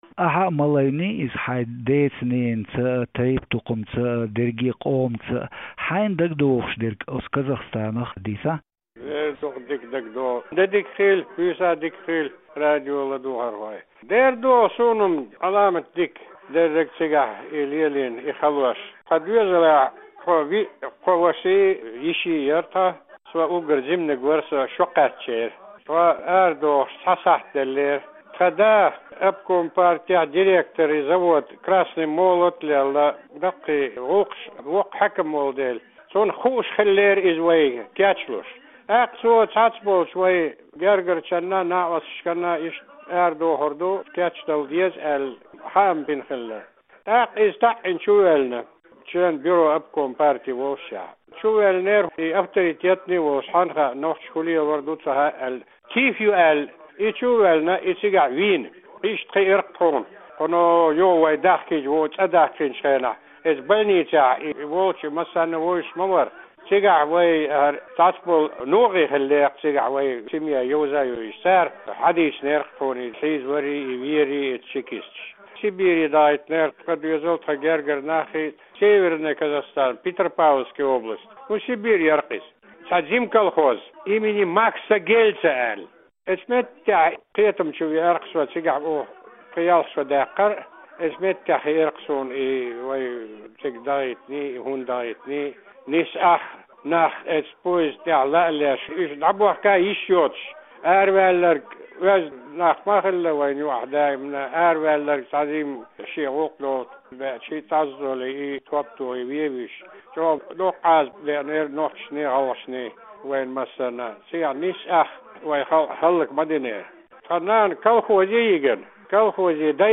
Вайнах махкахбахарх интервью